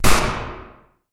Gun Shot
Category: Sound FX   Right: Personal